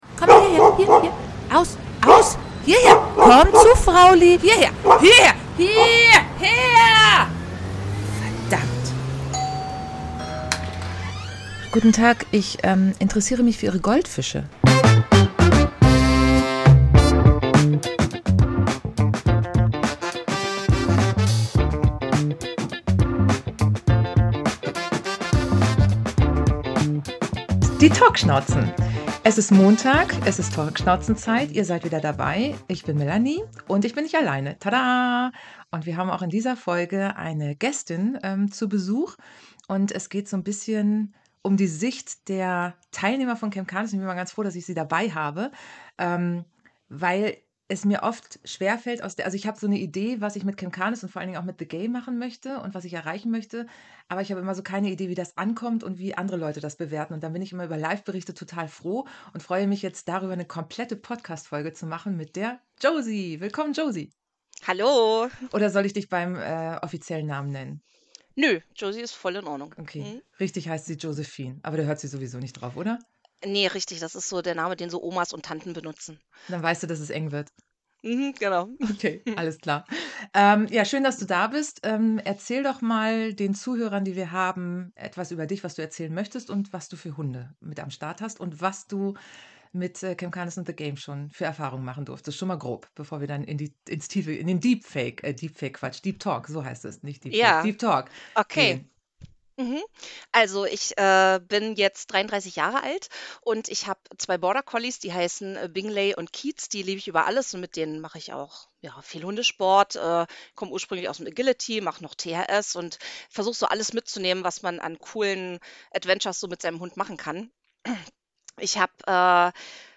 Was für ein spannendes Gespräch über ein spannendes Event.